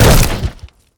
wood_hit2_hl2.ogg